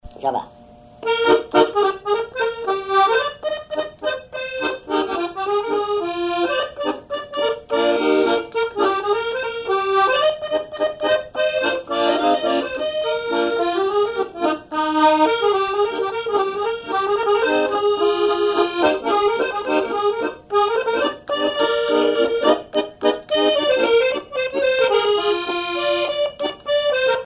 accordéon(s), accordéoniste
Vendée
danse : java
Genre strophique
Répertoire à l'accordéon chromatique
Pièce musicale inédite